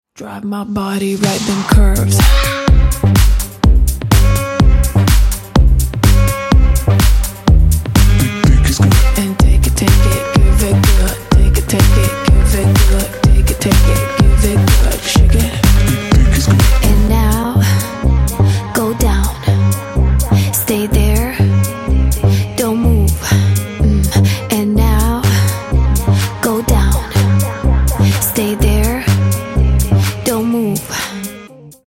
Minőség: 320 kbps 44.1 kHz Stereo